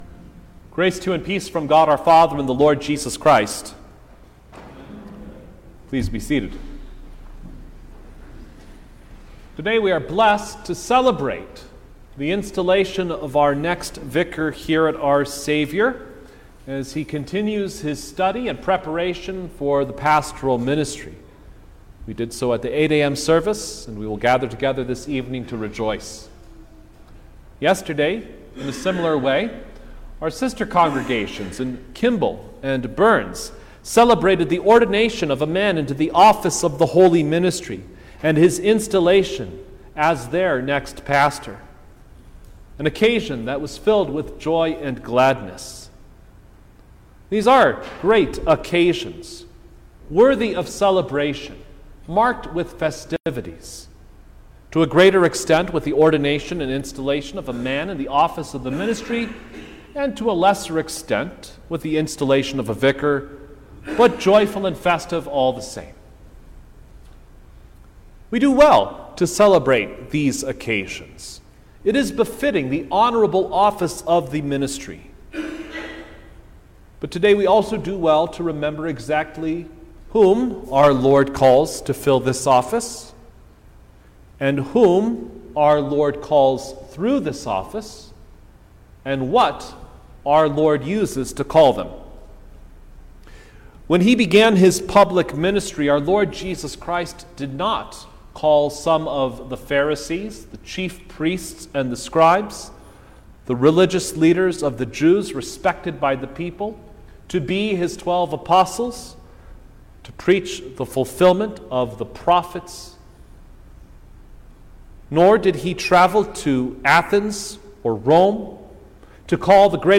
July-9_2023_Fifth-Sunday-after-Trinity_Sermon-Stereo.mp3